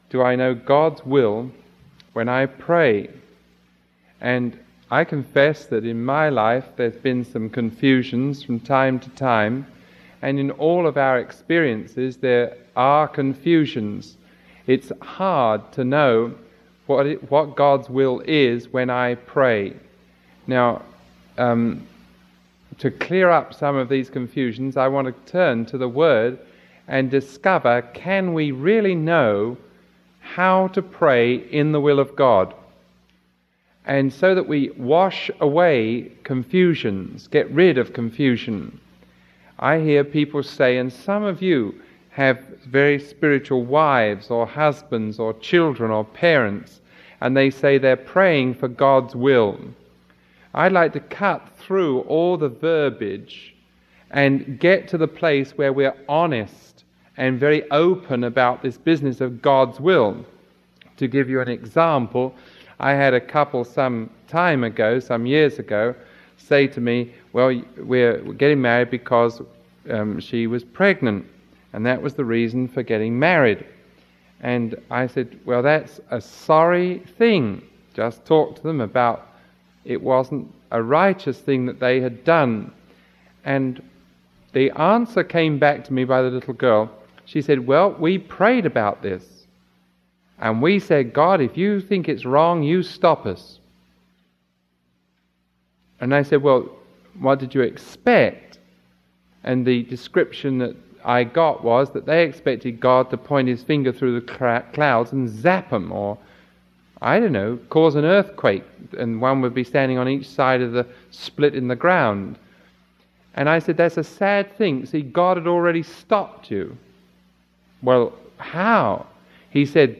Sermon 0067AB recorded on June 20